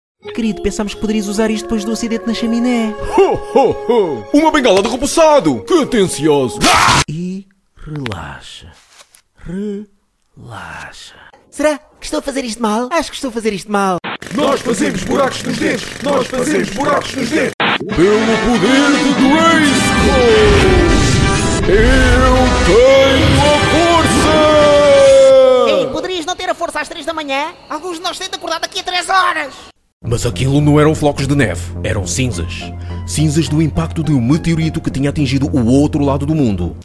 Animation
Also, I can do many voice tones: calm, energetic, friendly, aggressive, informative, funny, and many more.
If you want a versatile Portuguese male voice, contact me.
Microphone: Rode NT-1A large diaphragm